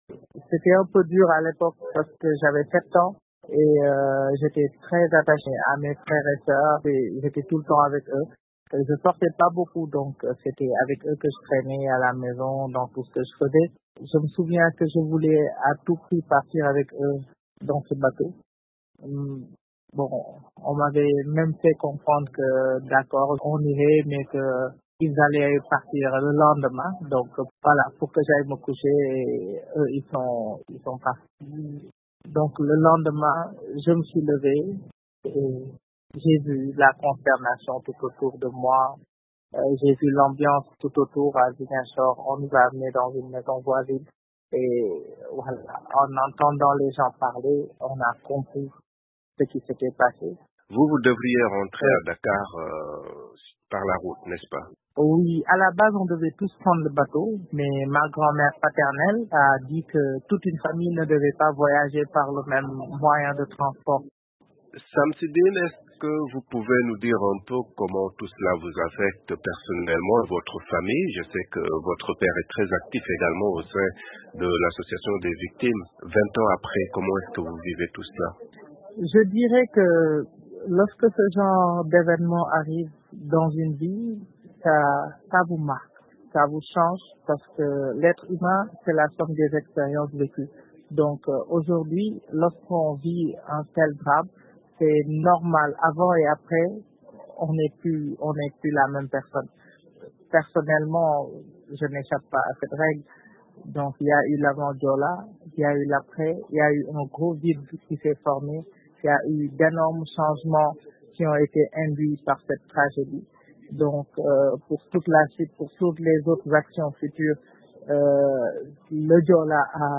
Naufrage du Joola: témoignage d'un proche de victimes, 20 ans après